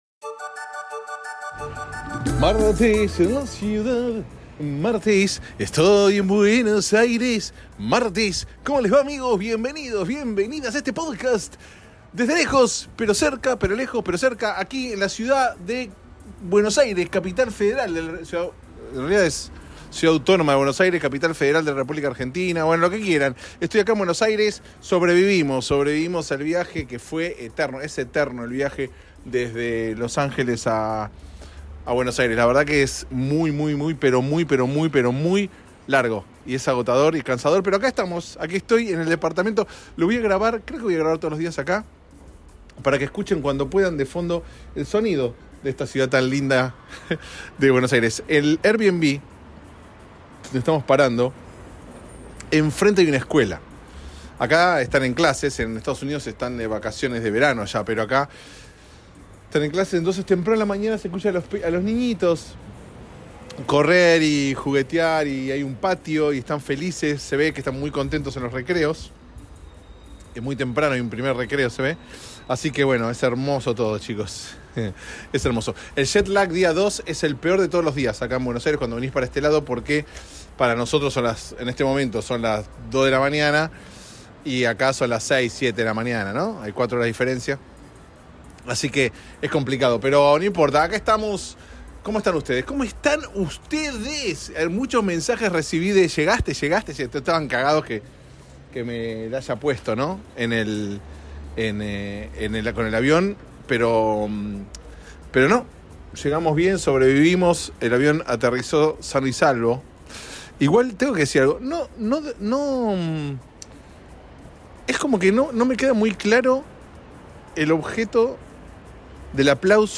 Una cosa de locos... grabado en las entrañas de Buenos Aires, Argentina.